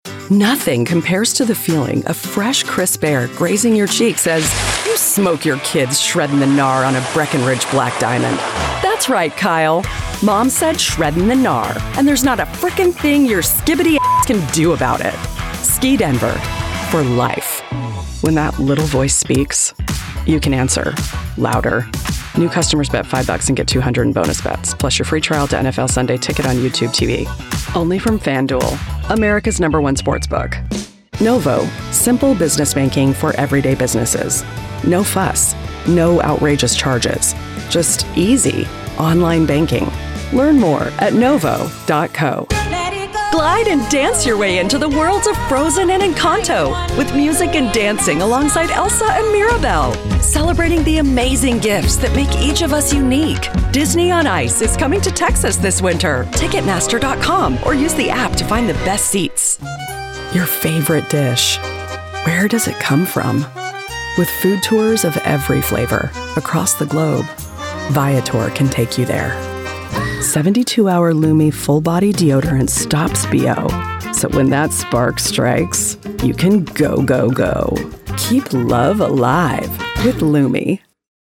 Wise, authentic, commanding, warm, young, friendly, sincere, and inclusive.
Neutral American